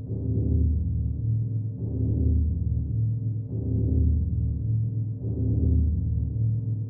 deck.ogg